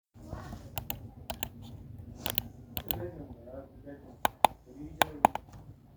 Sound Effects
Mouse Clicks
Mouse clicks.m4a